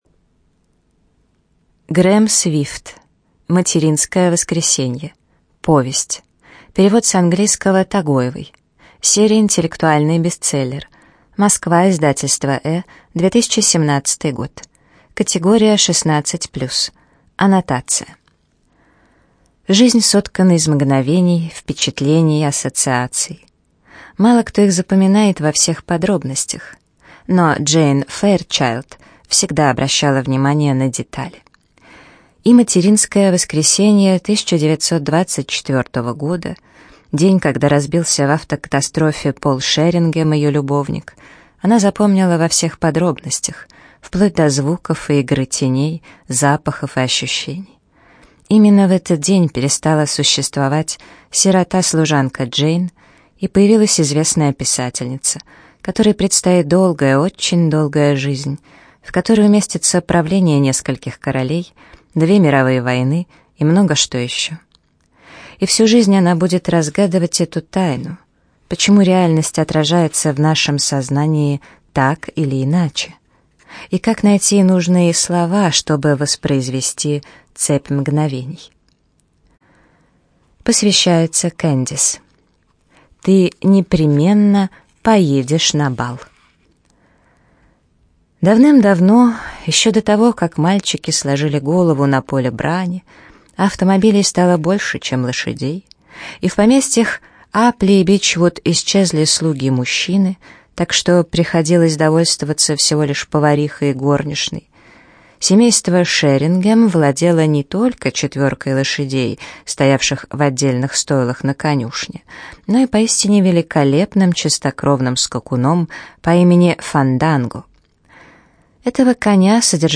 ЖанрИнтеллектуальная проза
Студия звукозаписиЛогосвос